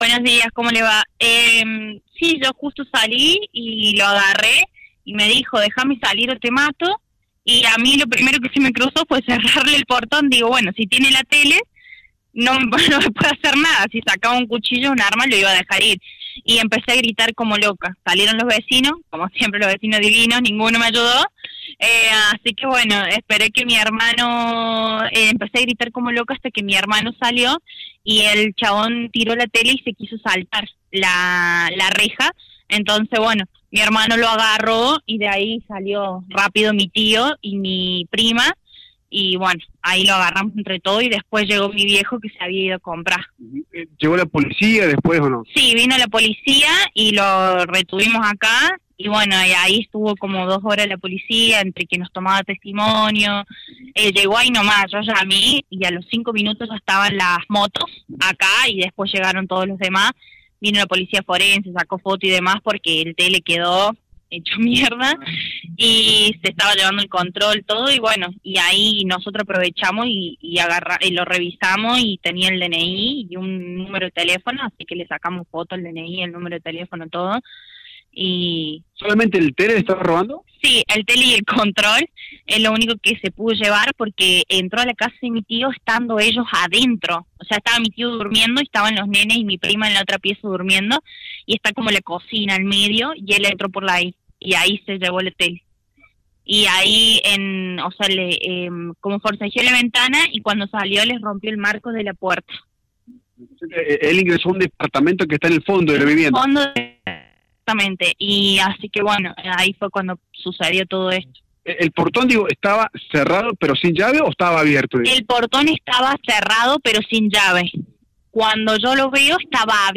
Integrantes de la familia que demoraron al delincuente que ingresó a robar a la vivienda hablaron en exclusiva con el móvil de Radio La Bomba y contaron la desesperante situación que les tocó vivir durante la noche de este día jueves. Atraparon al ladrón y se lo entregaron a la policía cuando llegó al lugar. Aseguran que el delincuente es conocido en el ambiente delictivo y los amenazó con que iba a volver en algún momento.